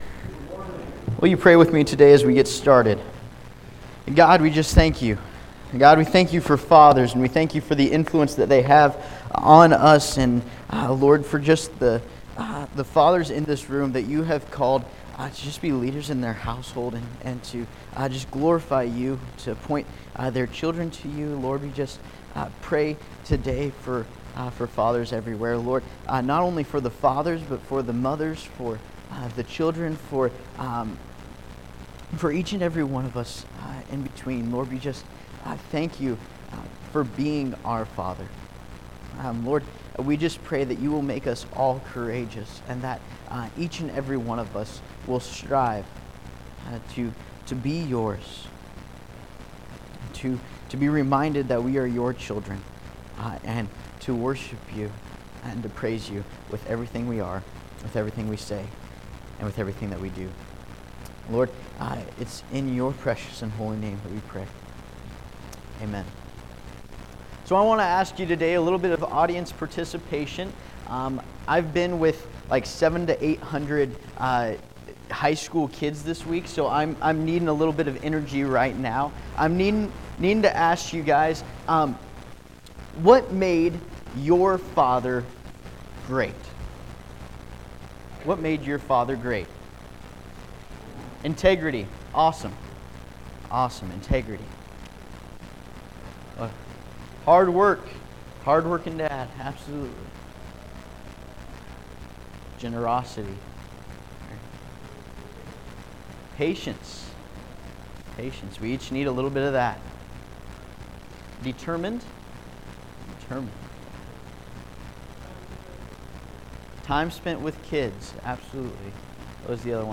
John 3:16 Service Type: Sunday Morning Topics